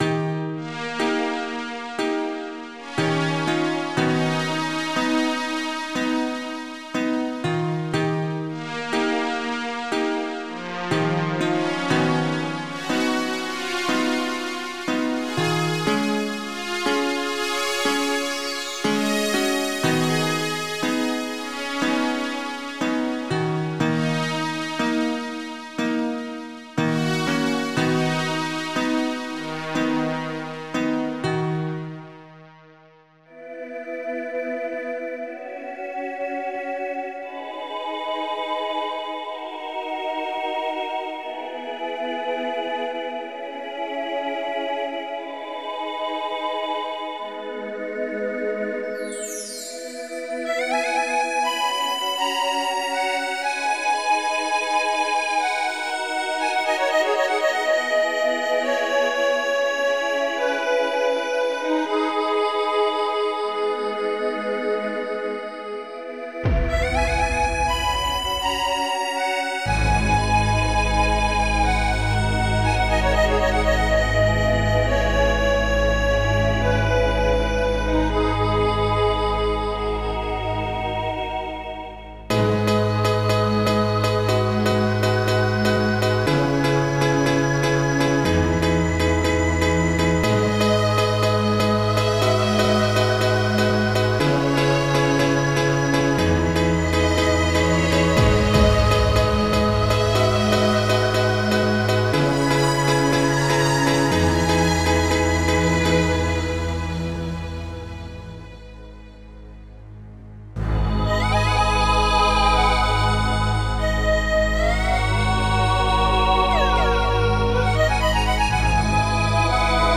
Impulse Tracker Module